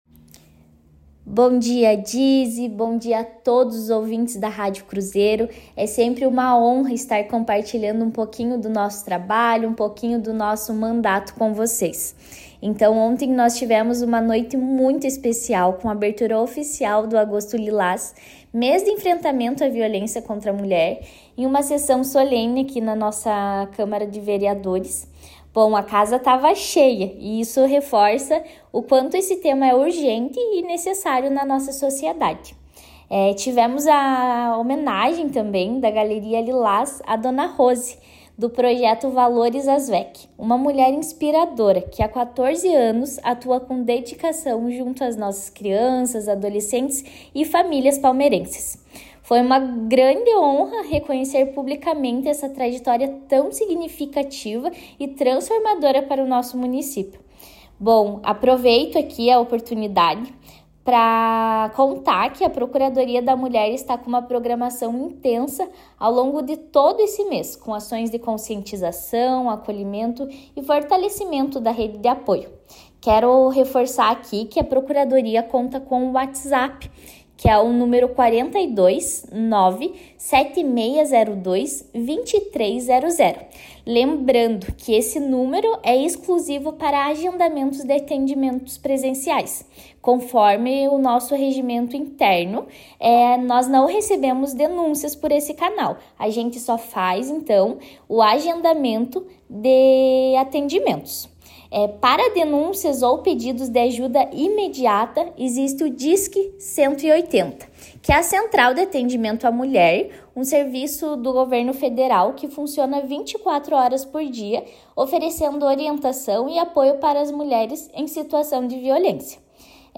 A vereadora Fabiola Meireles conversou com a Cruzeiro FM sobre a abertura do Agosto Lilás, mês dedicado à conscientização e combate à violência contra a mulher. Durante a entrevista, Fabiola ressaltou a importância da mobilização da sociedade e do poder público no enfrentamento à violência de gênero, destacando os canais de denúncia e apoio às vítimas, como os serviços locais de acolhimento.